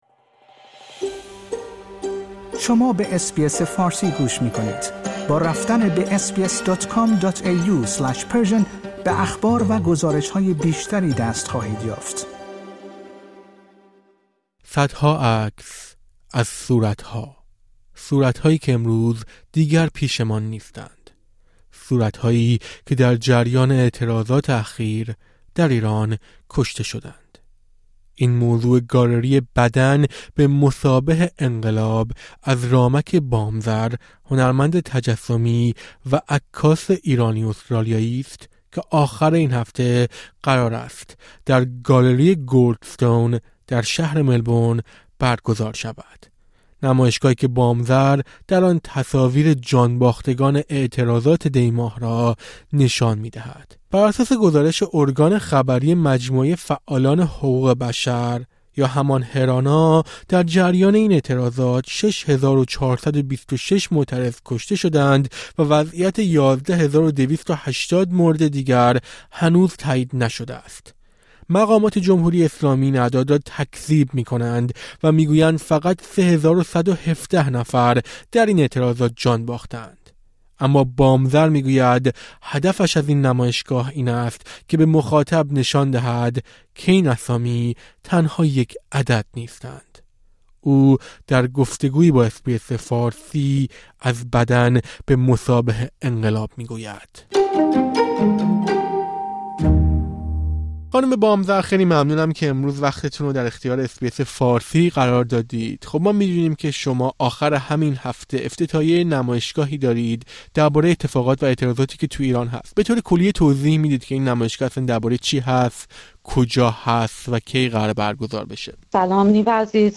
او در گفت‌وگویی با اس‌بی‌اس فارسی در این رابطه توضیح می‌دهد.